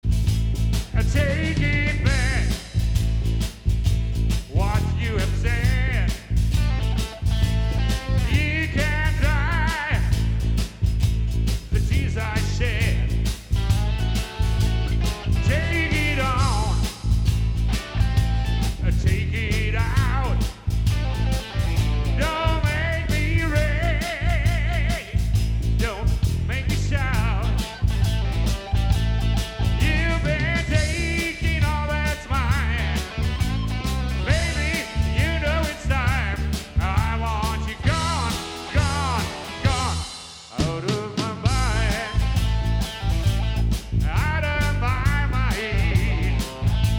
(Proberaumaufnahme)